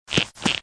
AV_side_step.ogg